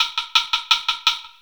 Ambient Wood 01.wav